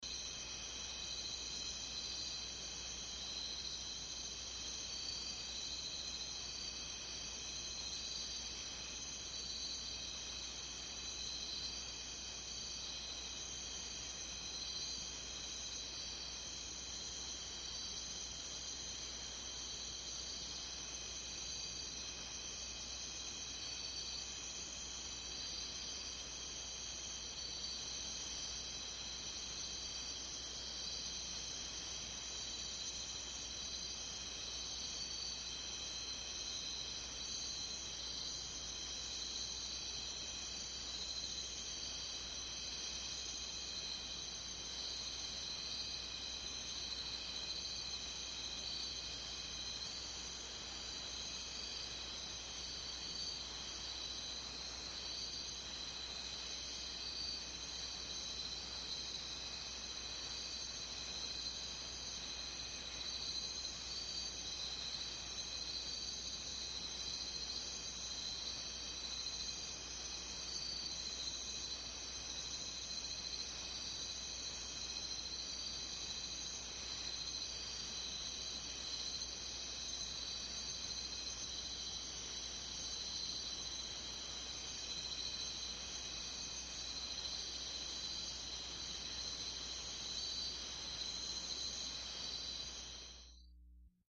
Guatemalan jungle at night, crickets only, El Mirador